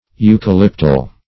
eucalyptol \eu`ca*lyp*tol\, n. [eucalyptus + L. oleum oil.]